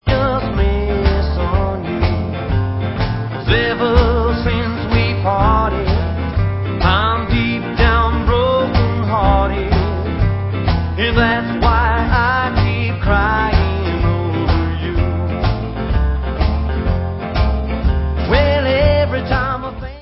Counrty swing with rockabilly flavors